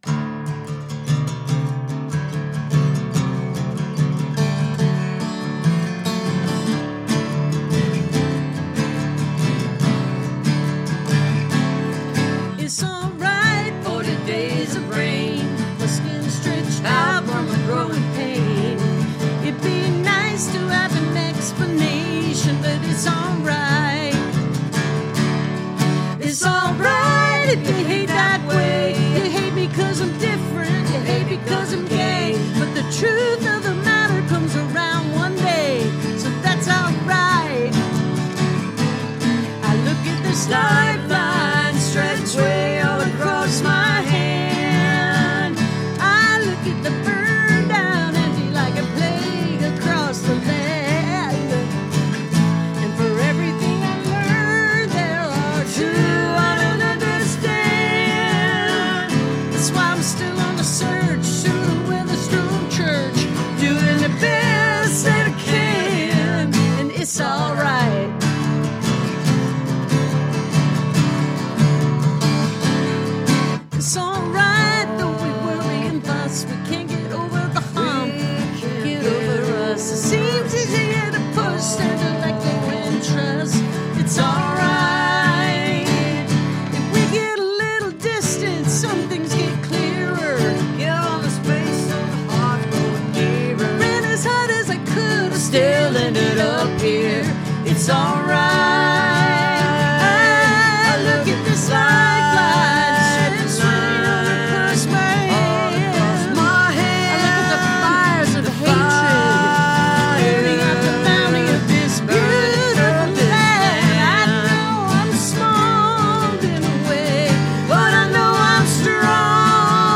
(captured from webstream)